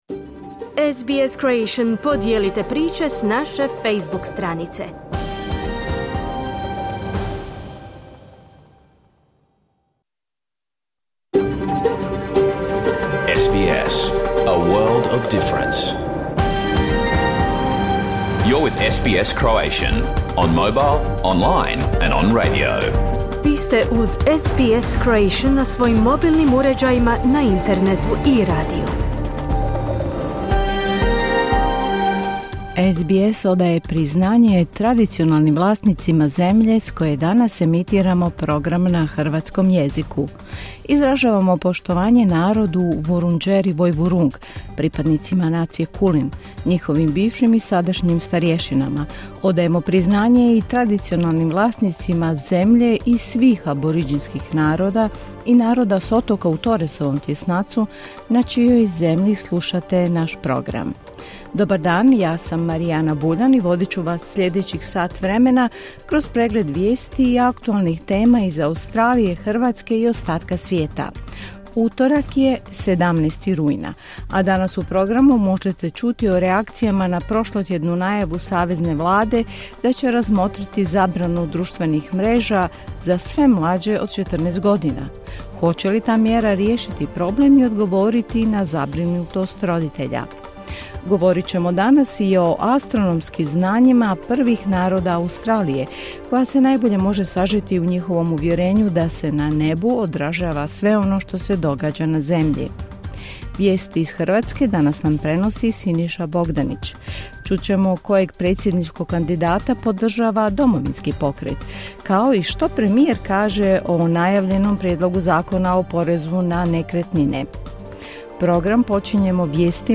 Vijesti i aktualne teme iz Australije, Hrvatske i ostaka svijeta. Emitirano uživo na radiju SBS1 u utorak, 17. rujna, s početkom u 11 sati po istočnoaustralskom vremenu.